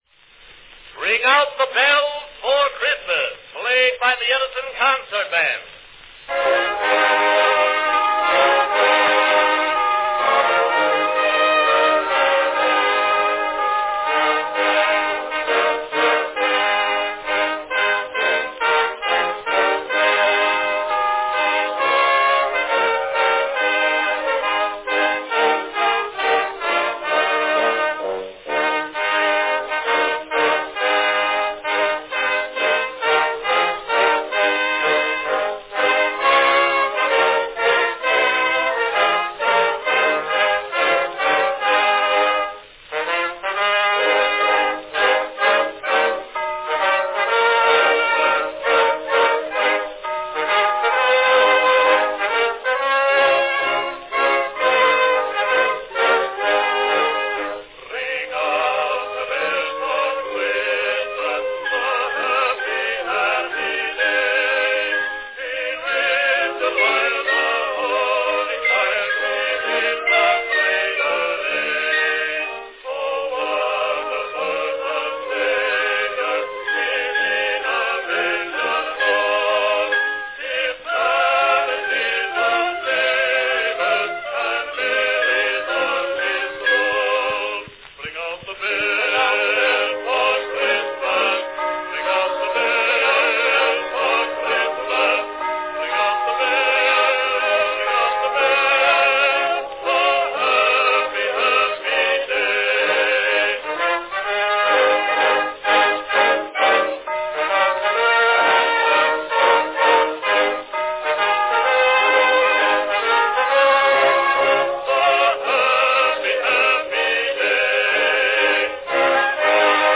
a cheery band and vocal duet number.
Category Band
Special arrangement, not published.